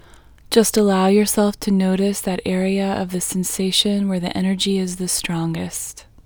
IN Technique First Way – Female English 5